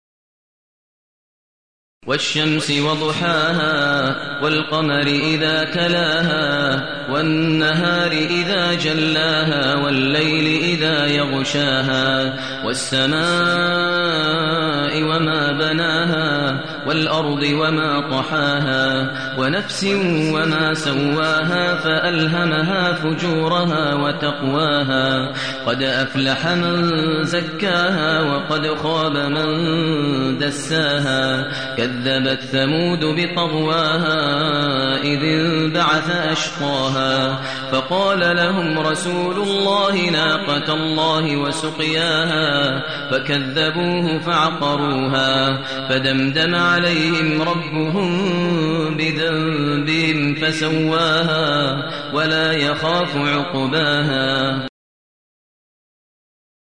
المكان: المسجد النبوي الشيخ: فضيلة الشيخ ماهر المعيقلي فضيلة الشيخ ماهر المعيقلي الشمس